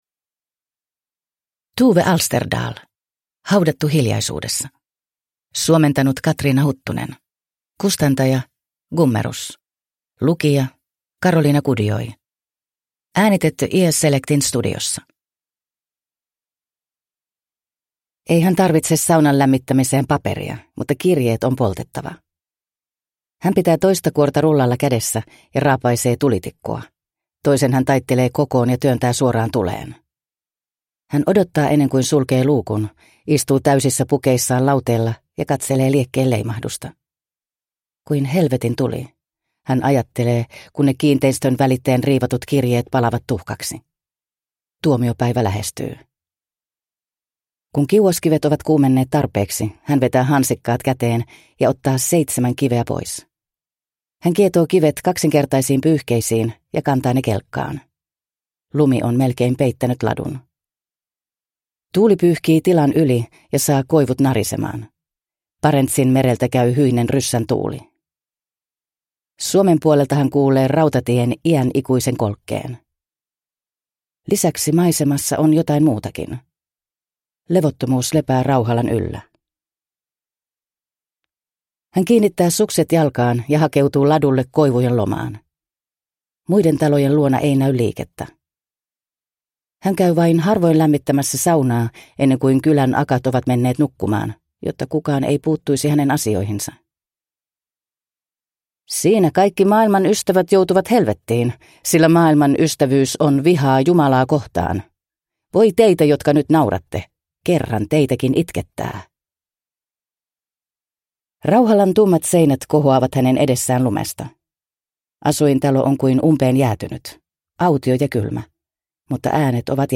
Haudattu hiljaisuudessa – Ljudbok – Laddas ner